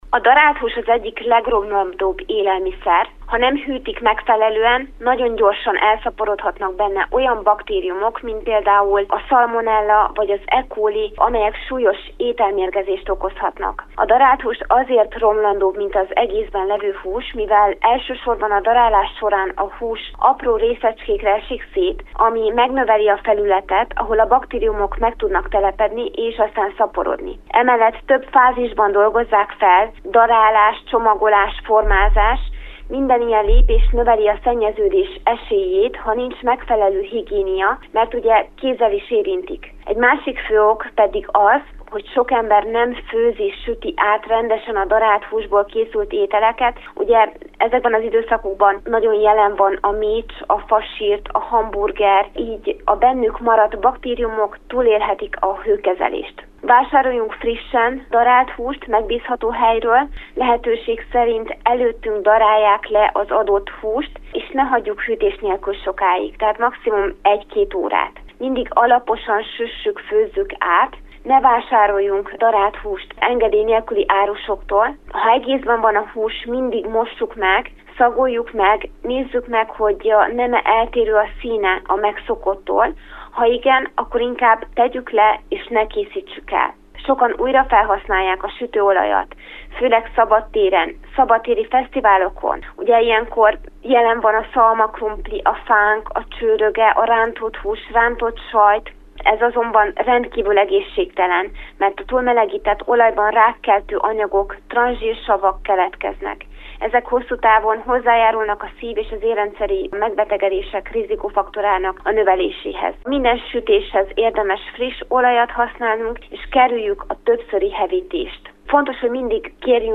Jótanácsok a dietetikustól a piknikezéshez.